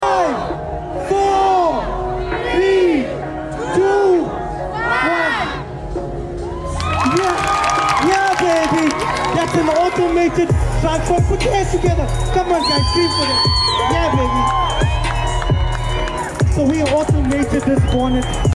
Remote control Automated Bonnet lift sound effects free download